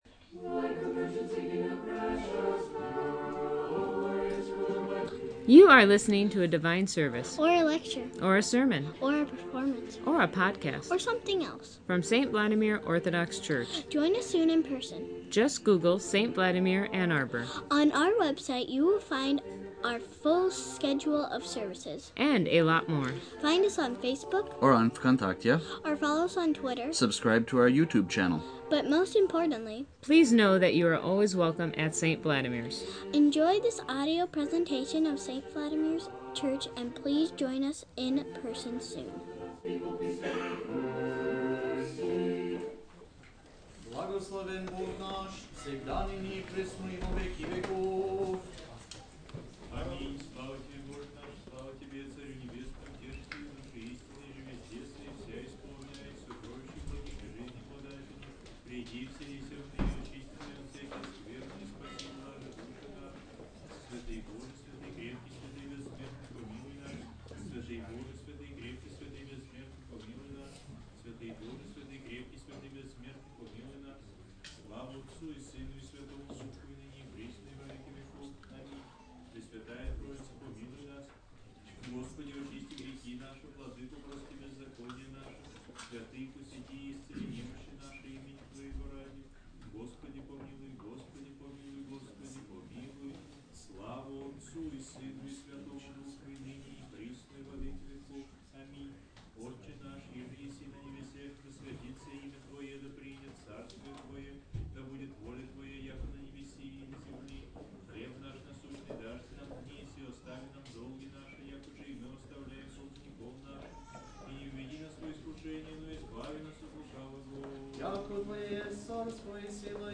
This interview is very worth listening to! We also have this week's announcements as well as liturgical highlights from the past week.